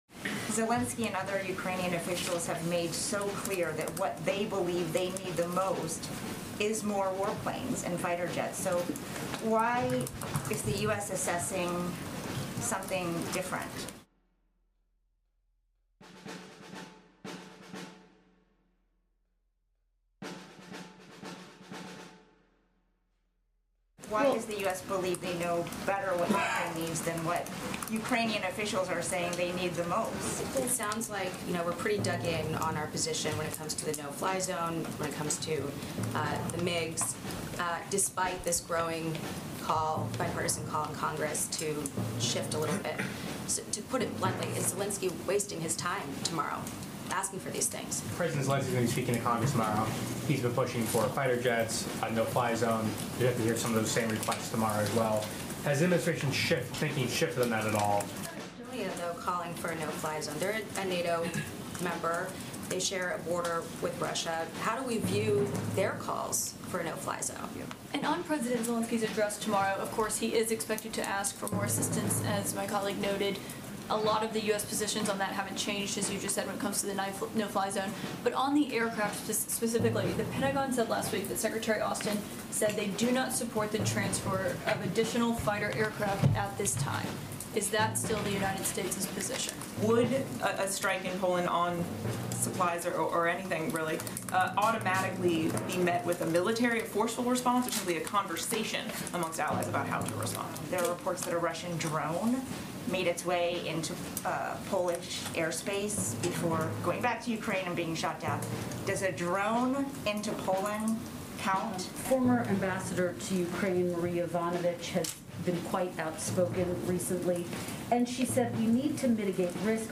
Qualitätspresse für Waffen statt Diplomatie in der Ukraine! Reporter fragten Pressesprecherin Jen Psaki, warum die Regierung nicht mehr unternehme, um die Ukraine zu bewaffnen.